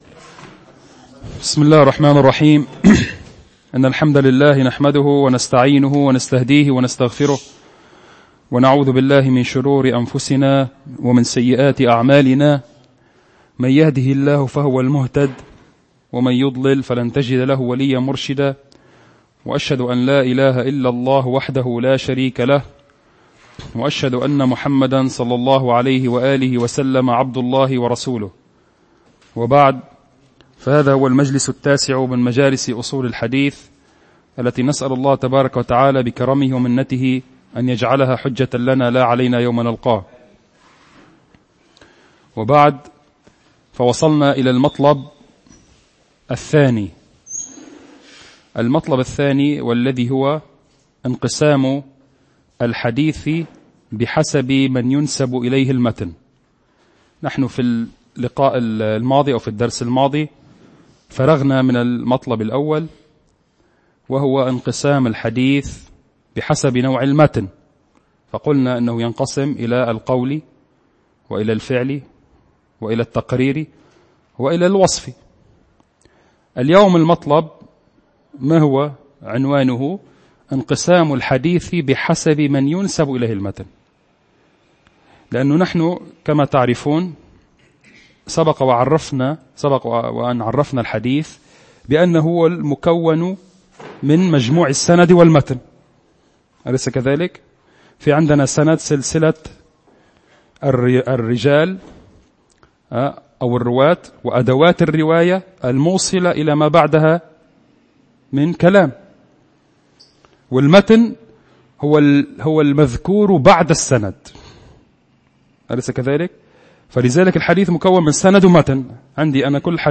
المكان : مركز جماعة عباد الرحمن